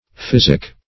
Physic \Phys"ic\ (f[i^]z"[i^]k), n. [OE. phisike, fisike, OF.